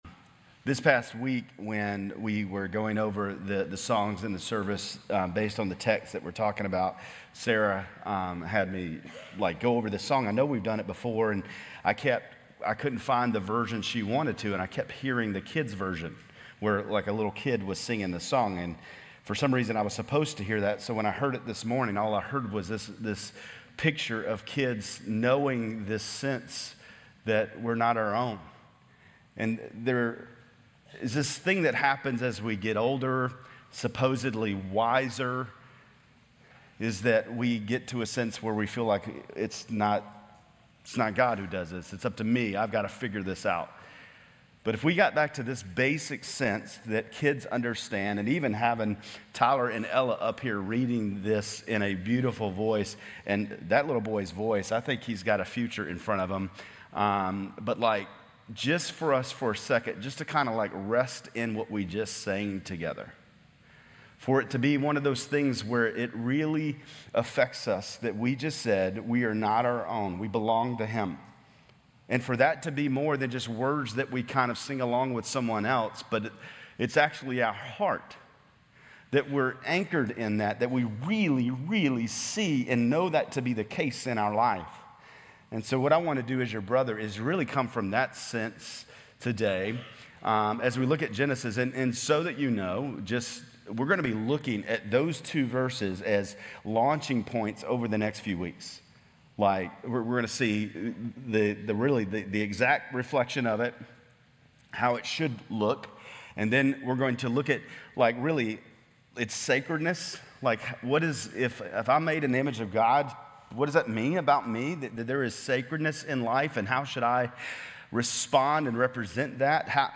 Grace Community Church Lindale Campus Sermons Genesis 1 - Image of God Aug 25 2024 | 00:31:15 1x 00:00 / 00:31:15 Subscribe Share RSS Feed Share Link Embed